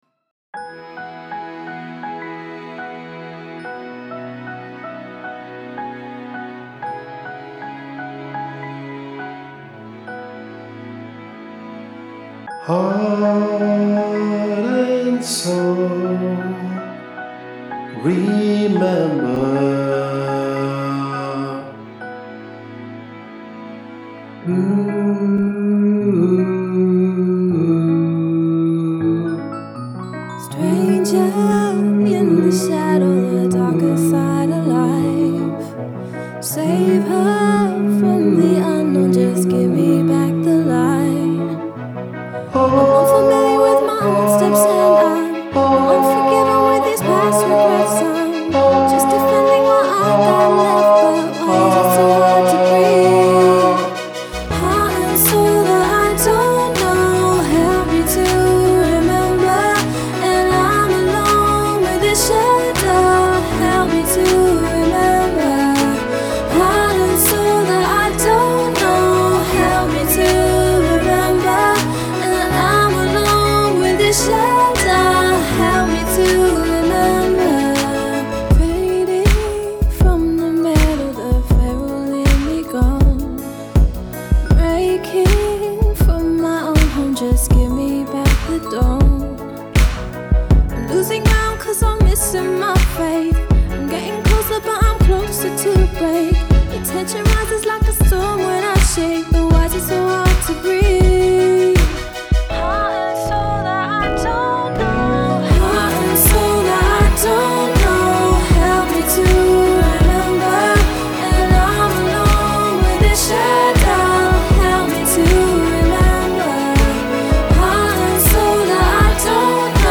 Choir
shadow-high.mp3